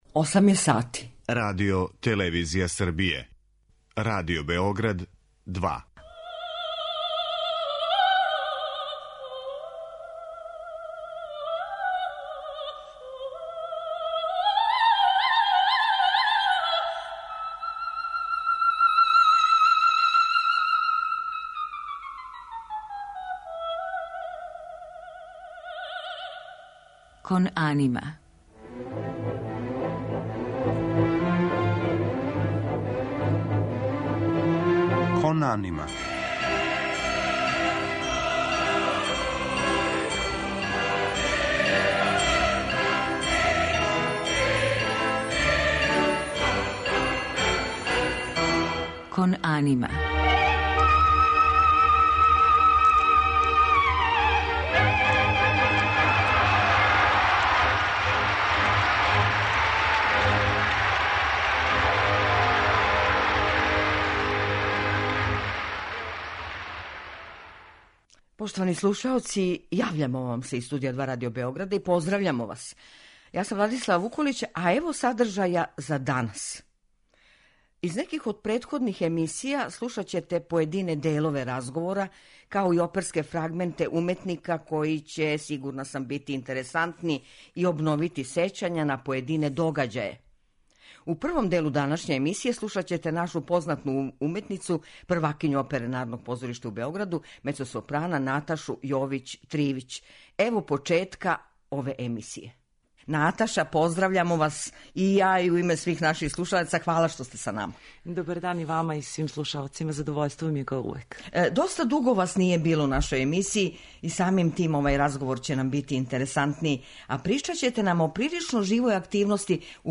Емисија о опери
У музичком делу биће емитоване арије из тенорског репертоара нашег данашњег госта.